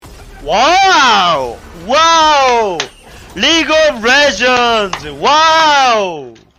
Download Wow League of Legends sound effect for free.